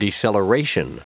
Prononciation du mot deceleration en anglais (fichier audio)
Prononciation du mot : deceleration
deceleration.wav